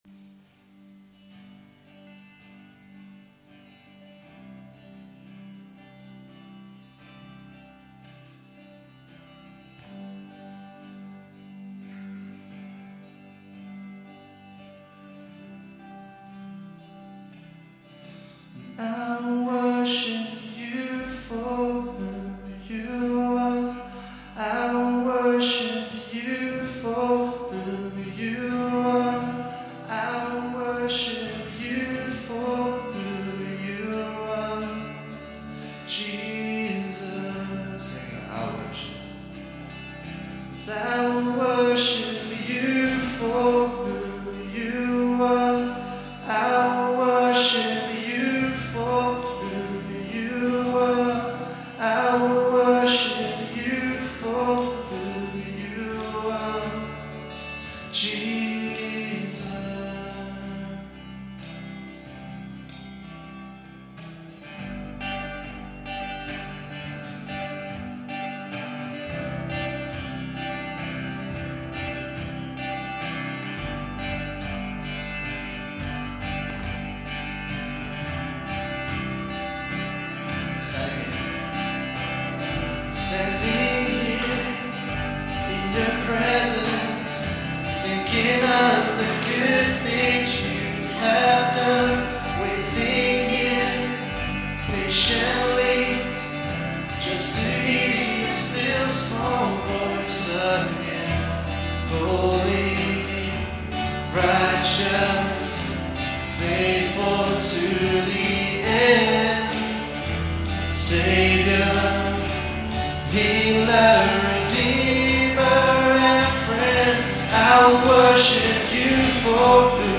Worship February 7, 2016